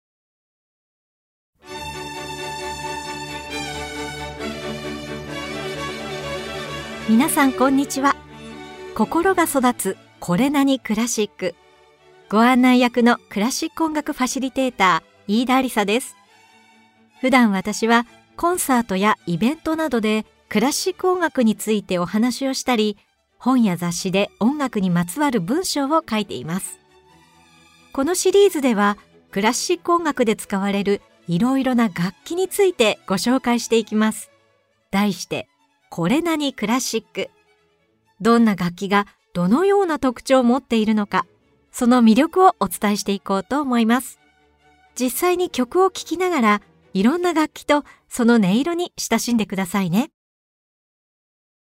[オーディオブック] 心が育つ これなに？クラシック 楽器大全 Vol.4 〜チェロ〜
実際に曲を聴きながら、いろんな楽器とその音色に親しんでください。